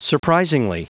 Prononciation du mot : surprisingly